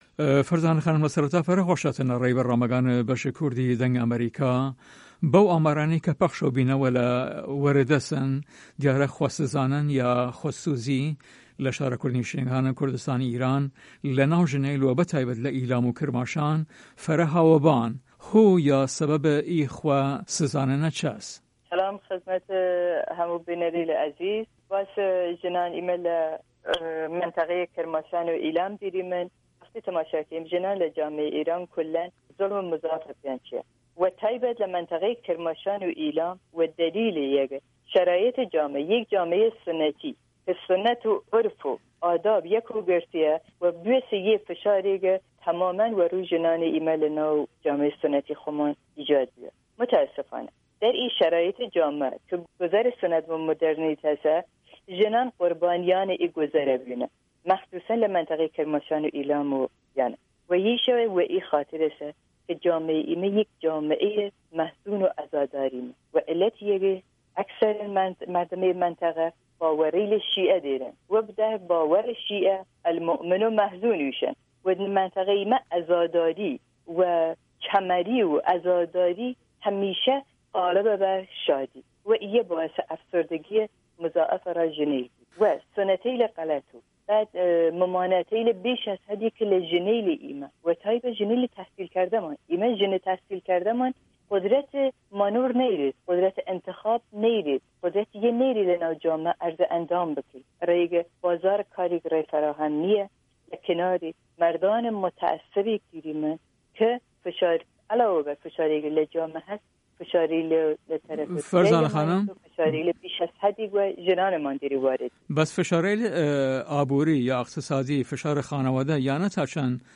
ئەم وتو وێژە بە زاراوەی ناوچەکانی ئیلام و کرماشان ئەنجام دراوە